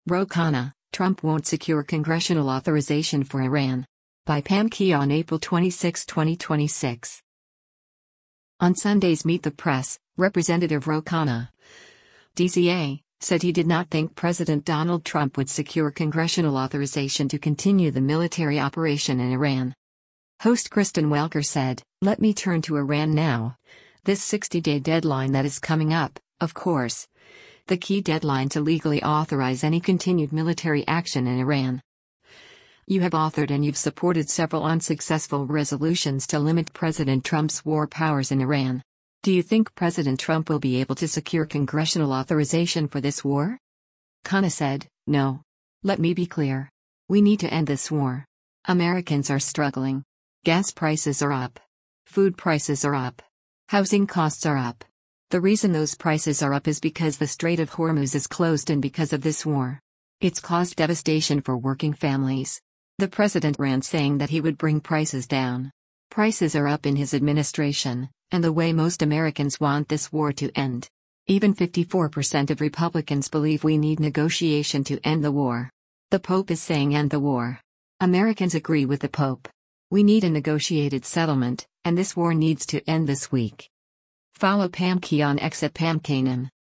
On Sunday’s “Meet the Press,” Rep. Ro Khanna (D-CA) said he did not think President Donald Trump would secure congressional authorization to continue the military operation in Iran.